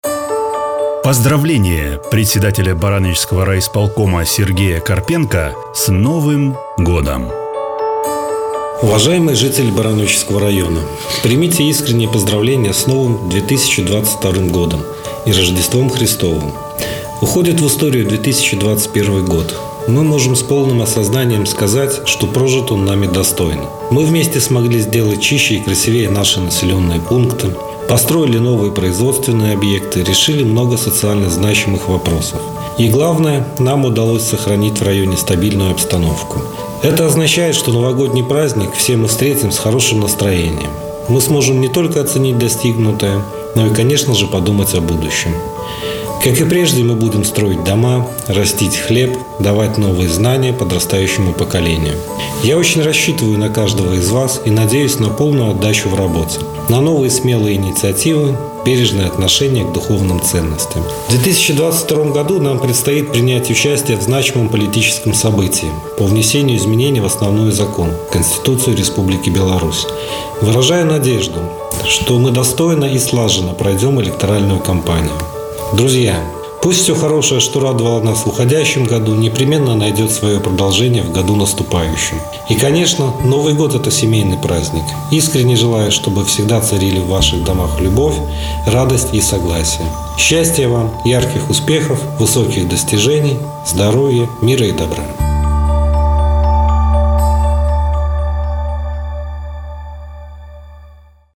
Поздравление с Новым годом председателя Барановичского районного исполнительного комитета Сергея Карпенко.